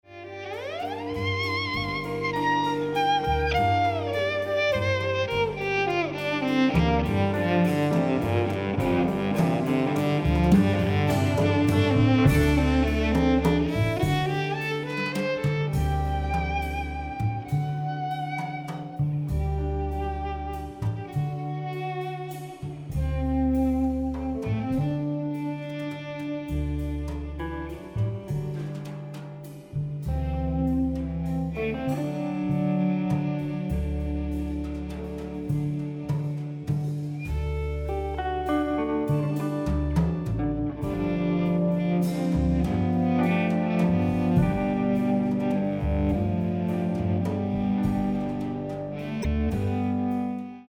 爵士大提琴跨界專輯